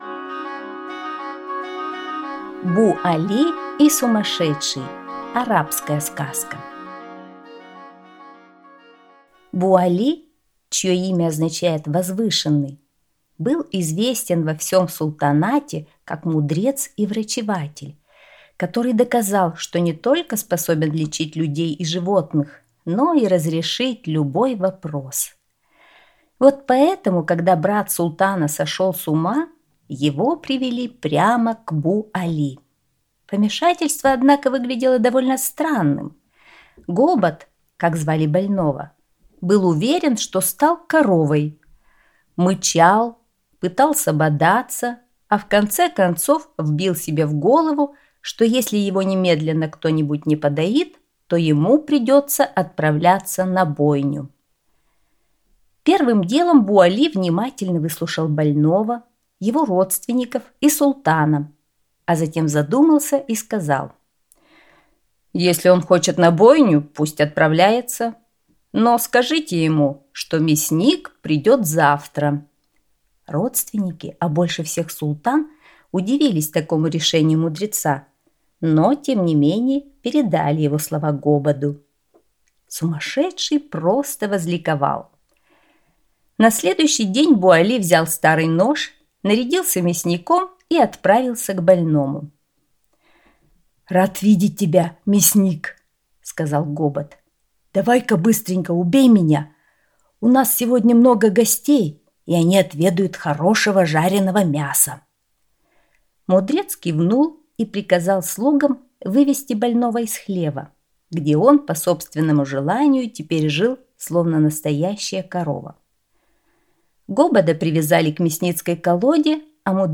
Бу Али и сумасшедший – арабская аудиосказка